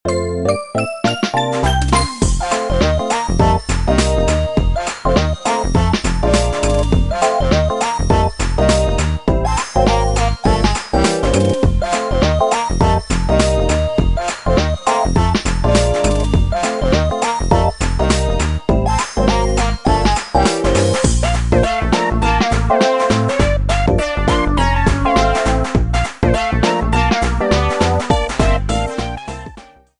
minigame theme rearranged